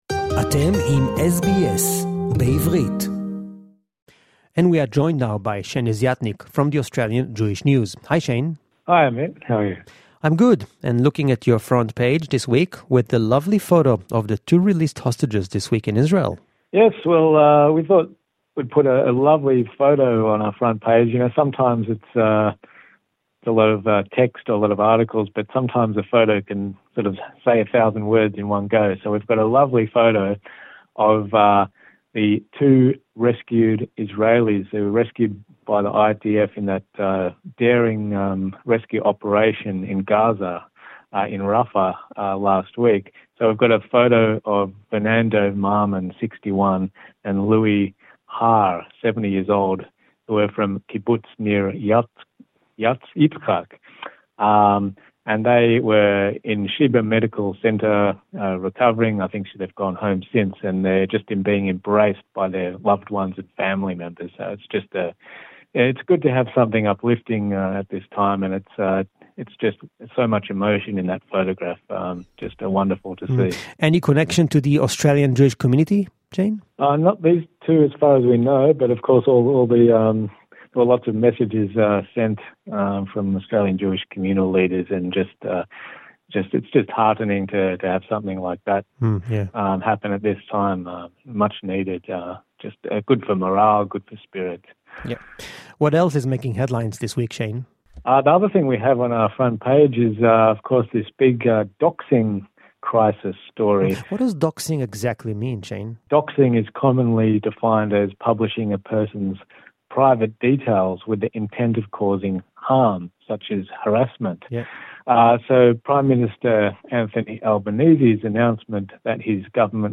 reporting to SBS Hebrew, with the latest Australian Jewish News.